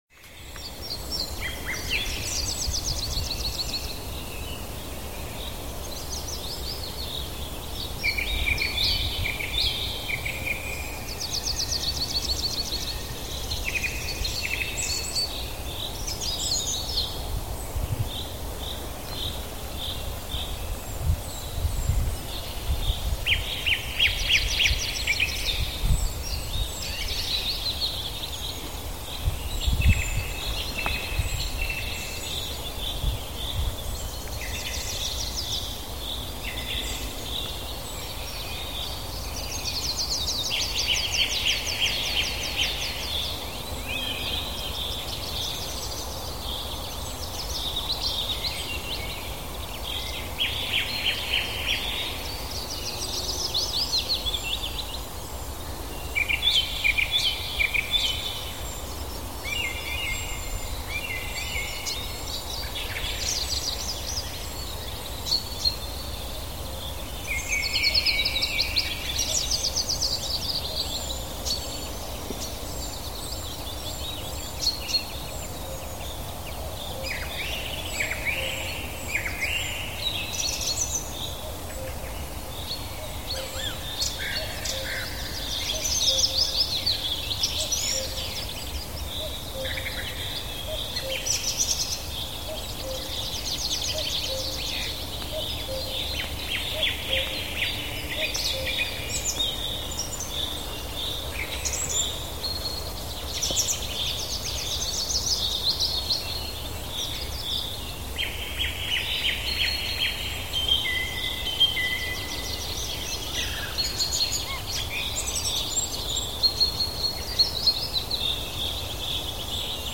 Calm Prairie Ambience for Mindfulness for Emotional Relief – Relaxing Mind Journey with Subtle Rainfall for Meditation and Healing
Each episode of Send Me to Sleep features soothing soundscapes and calming melodies, expertly crafted to melt away the day's tension and invite a peaceful night's rest.